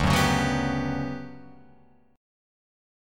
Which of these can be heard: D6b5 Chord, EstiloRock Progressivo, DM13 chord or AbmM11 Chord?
DM13 chord